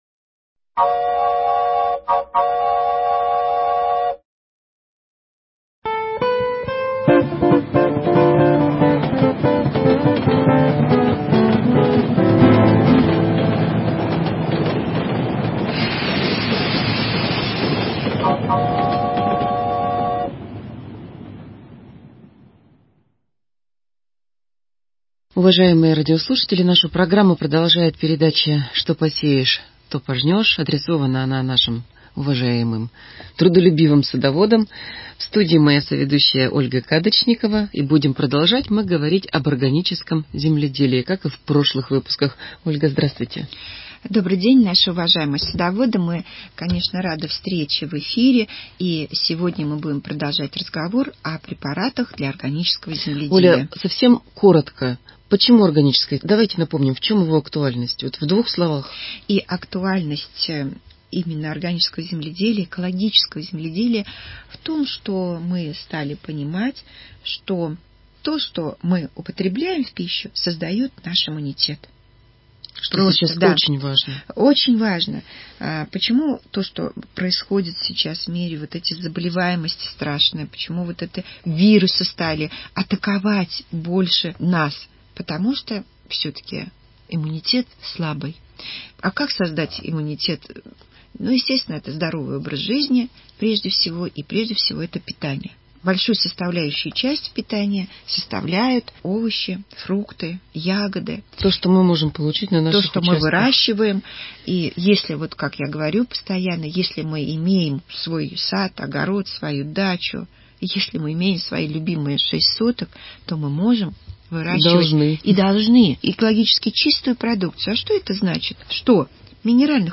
Передача для садоводов и огородников.